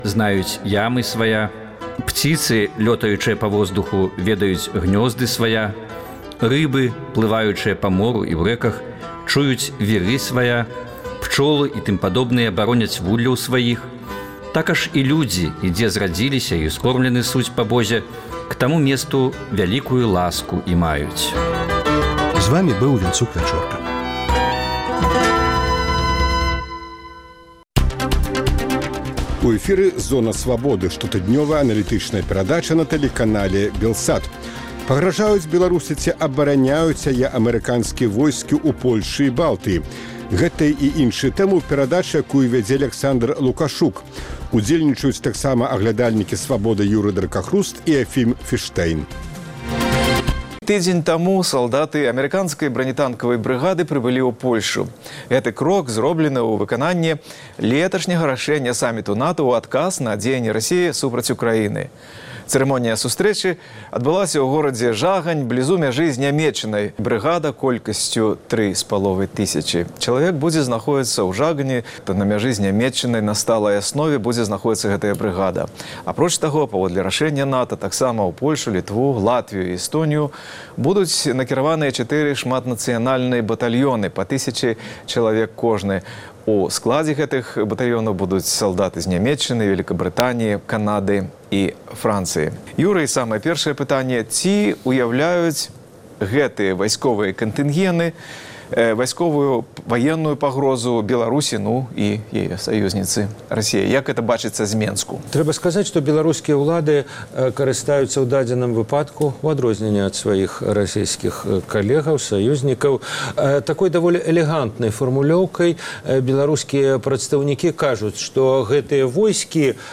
Зона Свабоды - штотыднёвая аналітычная перадача на тэлеканале Белсат Пагражаюць Беларусі ці абараняюць яе амэрыканскія войскі ў Польшчы і Балтыі? НАТА і Расея – молат і кавадла для Беларусі Пра што могуць дамовіцца Трамп і Пуцін?